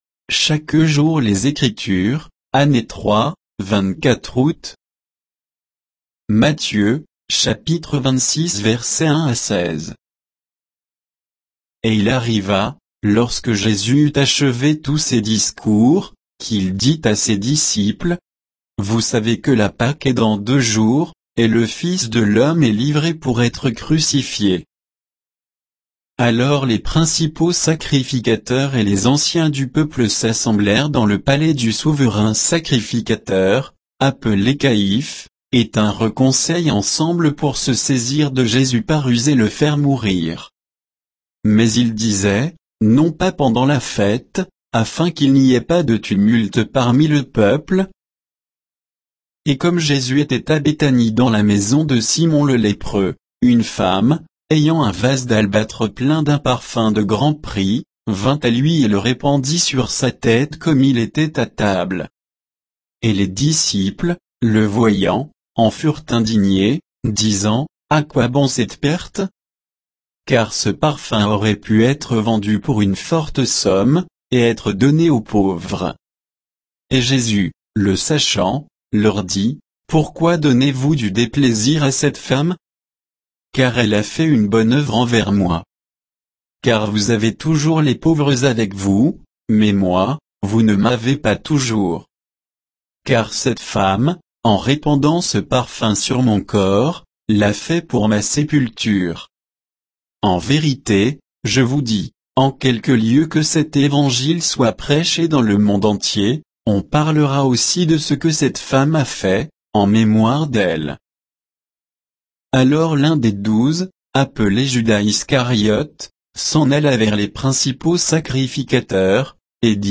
Méditation quoditienne de Chaque jour les Écritures sur Matthieu 26, 1 à 16